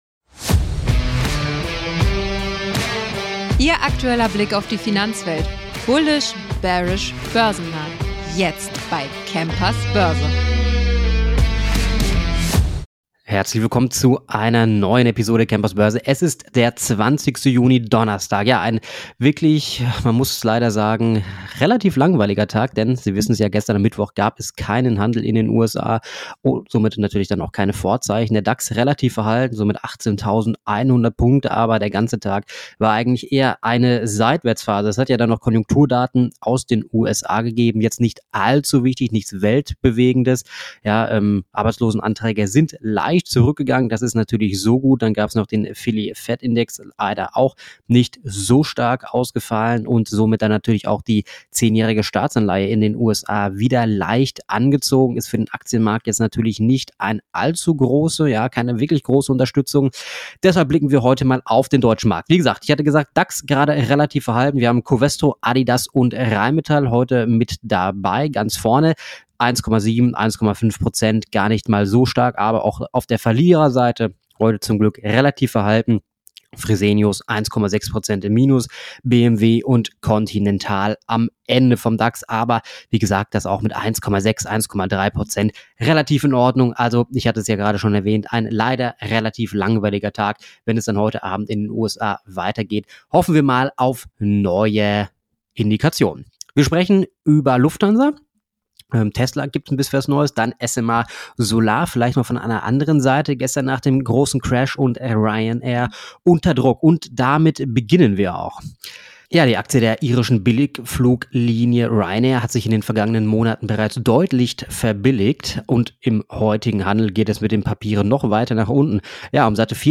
Er verfolgt die heißesten Aktien des Tages, deren Potenzial seine Gäste im Experten-Interview im Anschluss noch einmal genau analysieren.